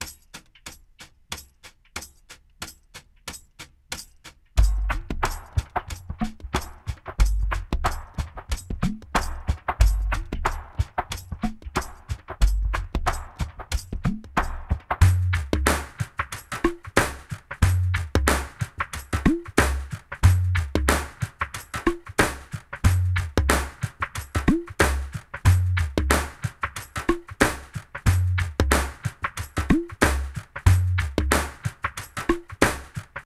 Ritmo de percusión 3 (bucle)
urbano
percusión
batería
binario
electrónico
rap
sintetizador